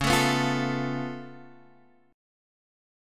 C#+7 chord